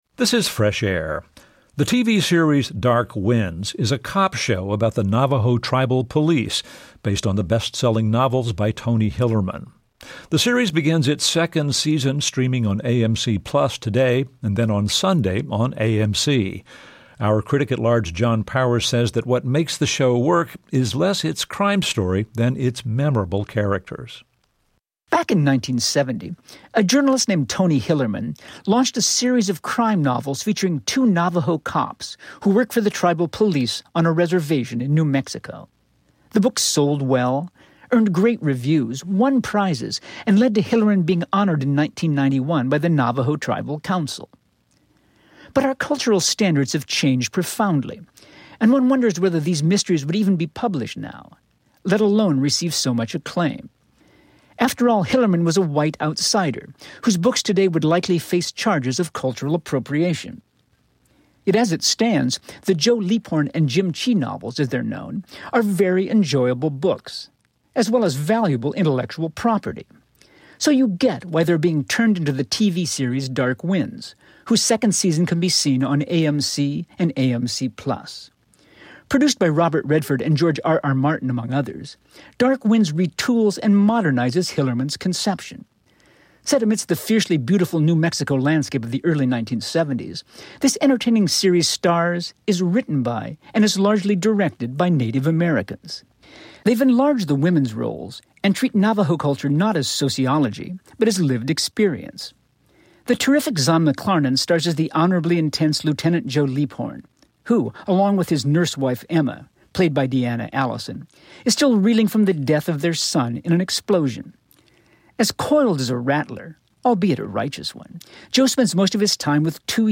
TV Review